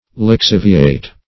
Lixiviate \Lix*iv"i*ate\, v. t. [imp.